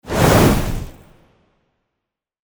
Fireball_1.mp3